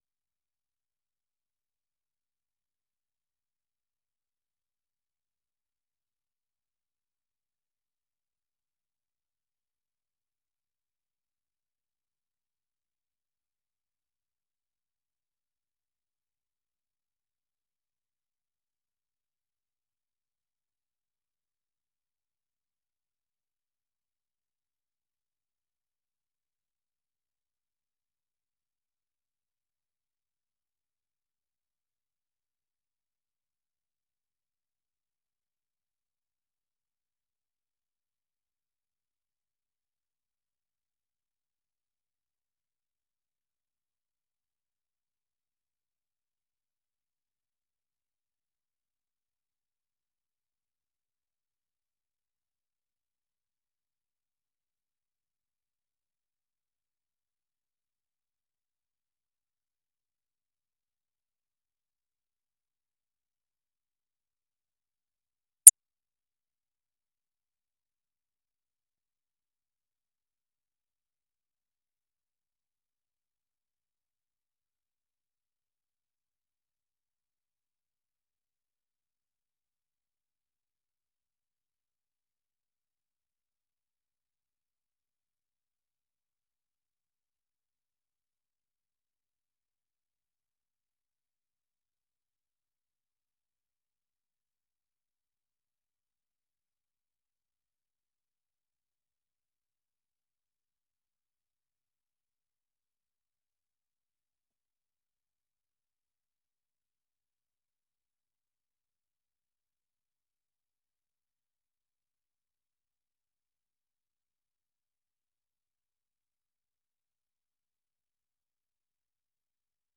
در برنامۀ رک و راست بحث‌های داغ صاحب‌نظران و تحلیلگران را در مورد رویدادهای داغ روز در افغانستان دنبال کرده می‌توانید. این برنامه زنده به گونۀ مشترک به زبان‌های دری و پشتو هر شب از ساعت هشت تا نه شب به وقت افغانستان پخش می‌شود.